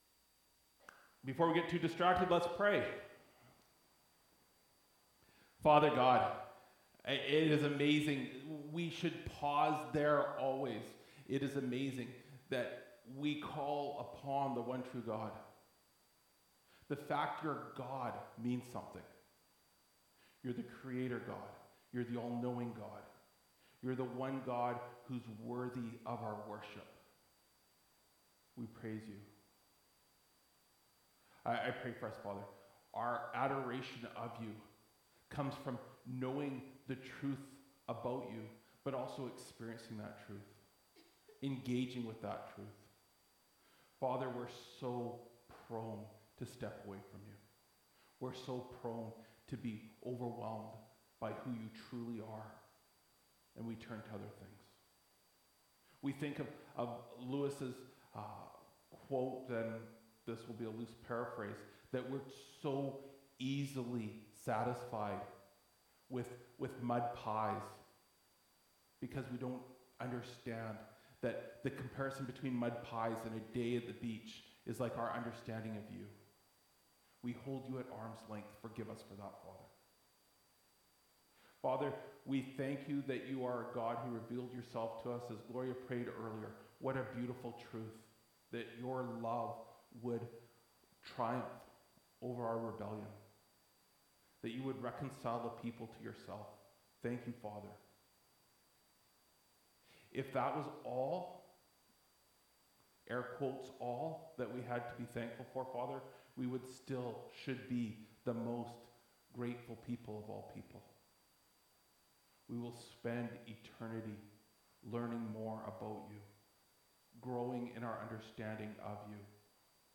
Nov 10, 2024 Strategic Church Planting (1 Corinthians 3:1-8) MP3 SUBSCRIBE on iTunes(Podcast) Notes Discussion Sermons in this Series This sermon was recorded in Salmon Arm and preached in both SA and Enderby.